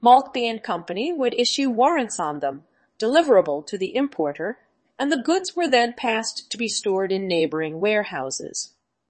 tortoise-tts - (QoL improvements for) a multi-voice TTS system trained with an emphasis on quality
tortoise.mp3